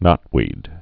(nŏtwēd)